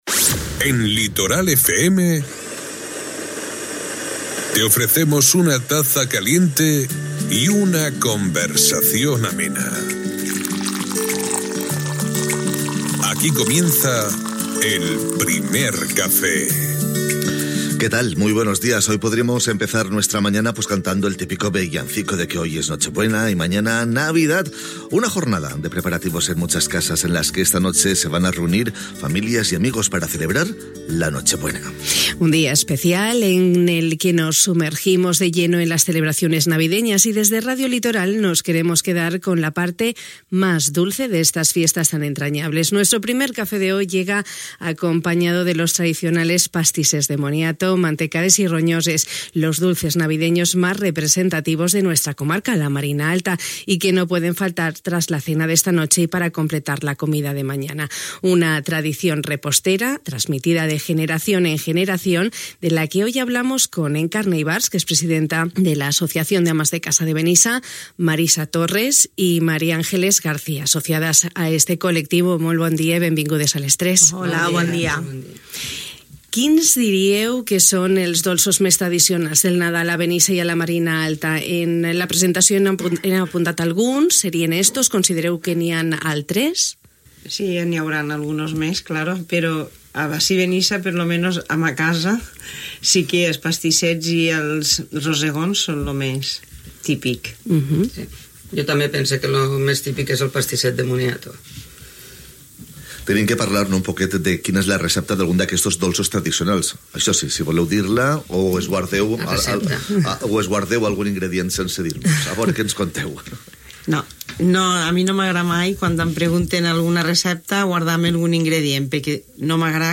Les nostres convidades han compartit la recepta dels pastissets de moniato, una tradició de la nostra reposteria, transmesa de generació en generació, que és continua elaborant quan arribin aquestes dates.